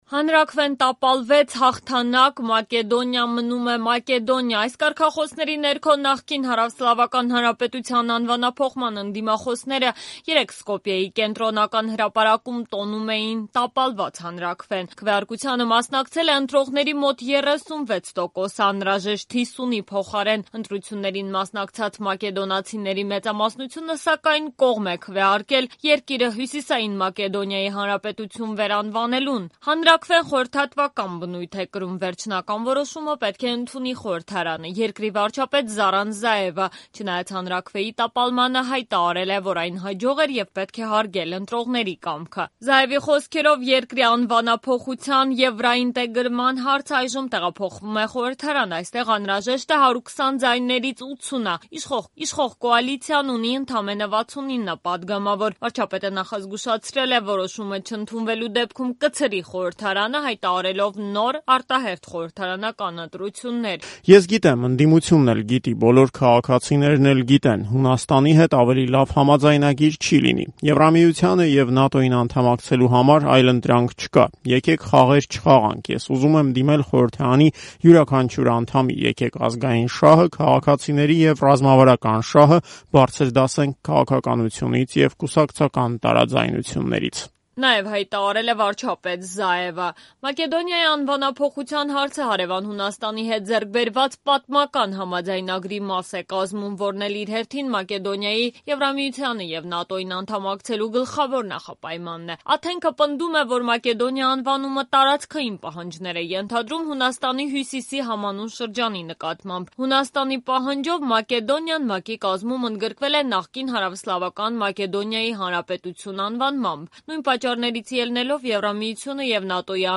«Մակեդոնիան մնում է Մակեդոնիա». ընդդիմախոսները Սկոպյեում տոնում էին տապալված հանրաքվեն
Ռեպորտաժներ